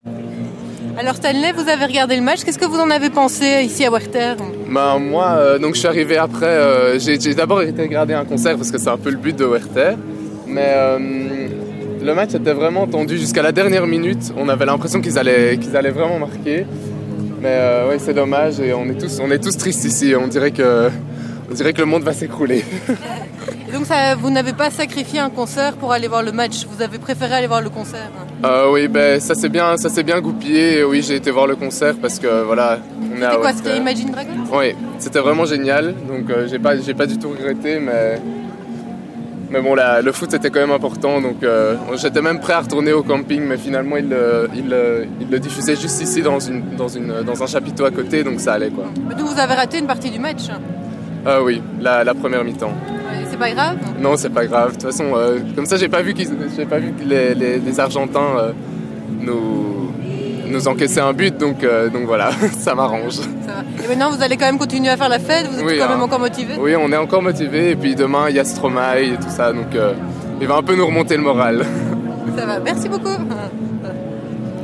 Reaction d'un fan des diables à werchter